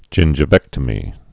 (jĭnjə-vĕktə-mē)